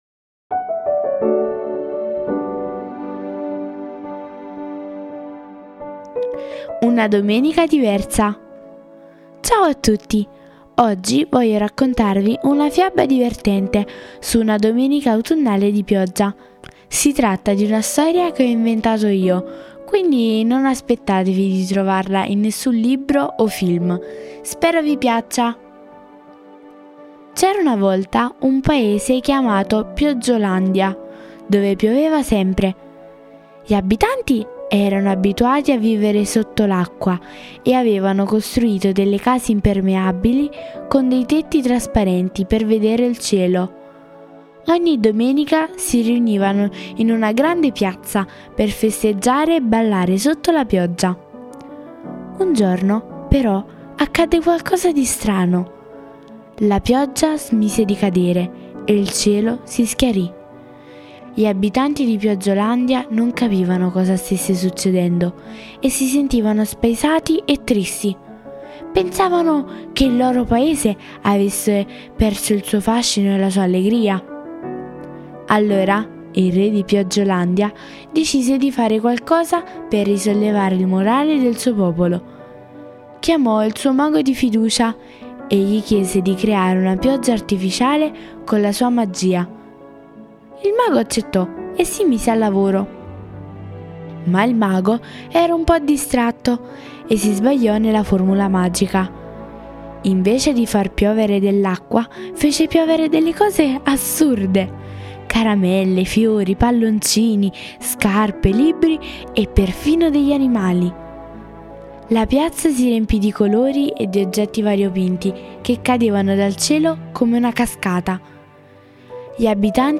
Le favole della buonanotte